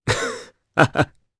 Dimael-Vox-Laugh_jp.wav